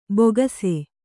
♪ bogase